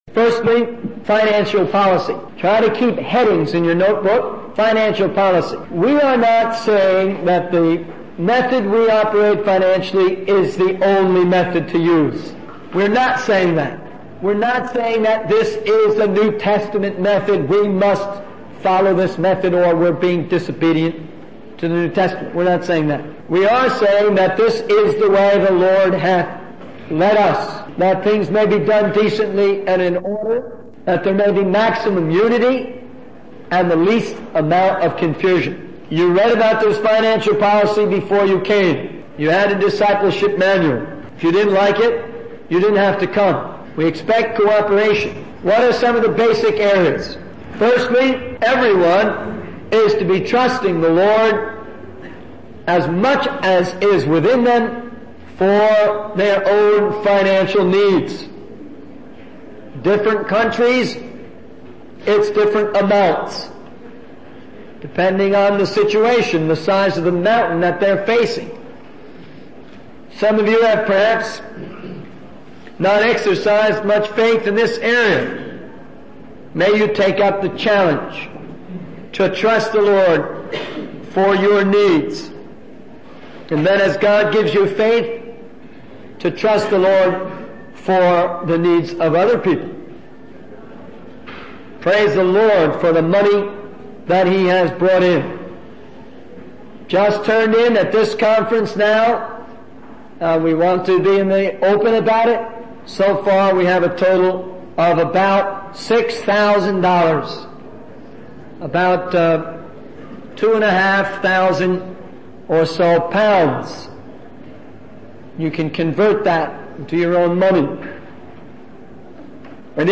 In this sermon, the speaker emphasizes the importance of giving testimonies that are clear, concise, and truthful. He advises against exaggerating or fabricating stories to make them more exciting.